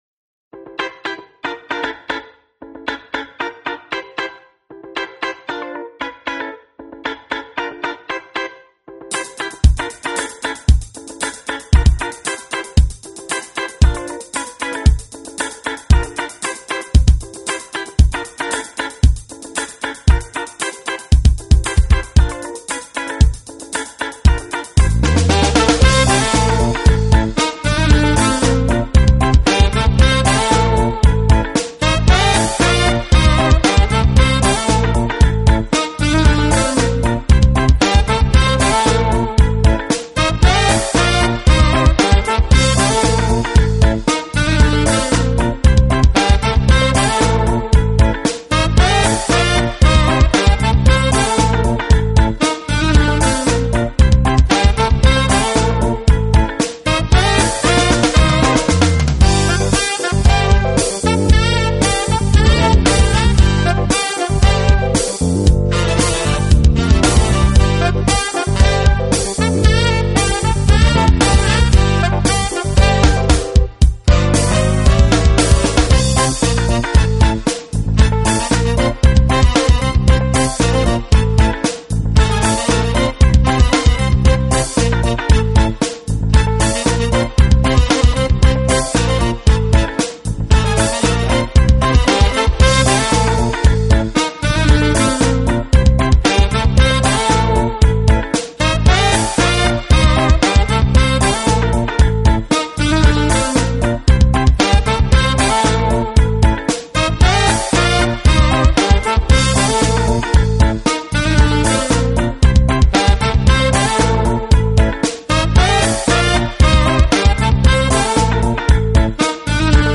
Genre: Jazz / Smooth Jazz
poppin' production, tight playing, and vibrant solos.